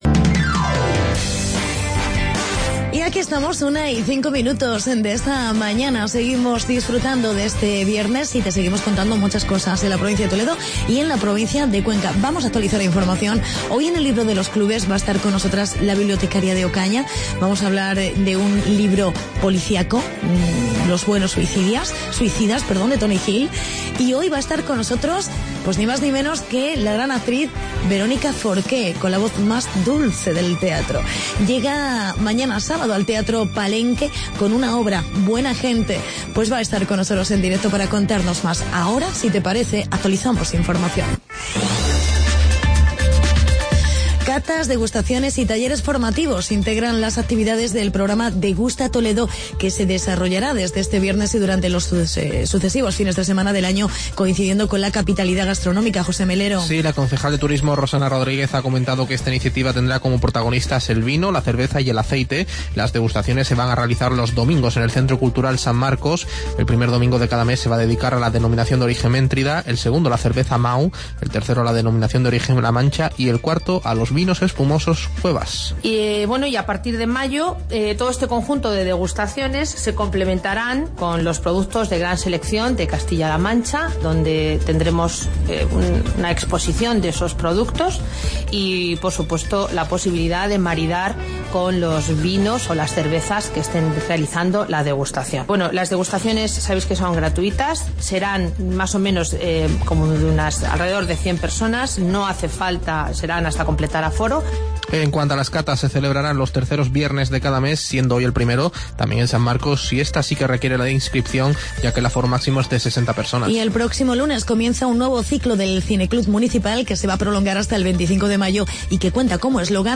Entrevista con la bibliotecaria